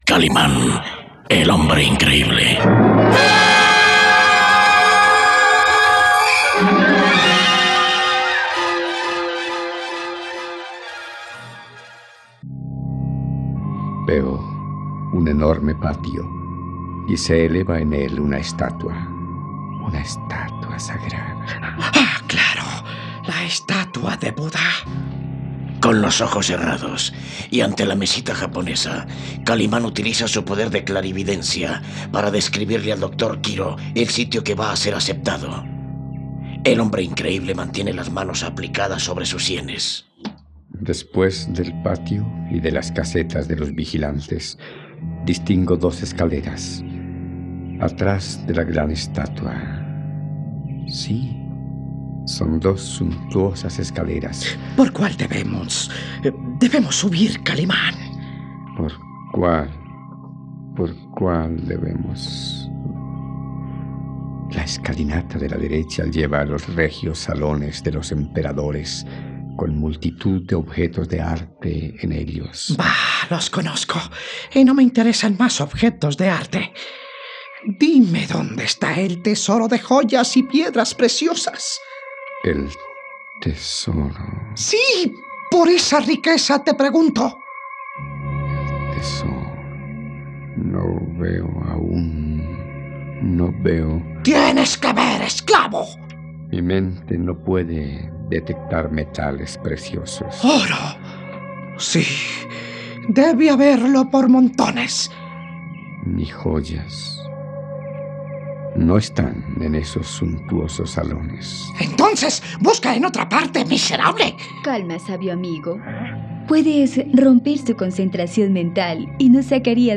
radionovela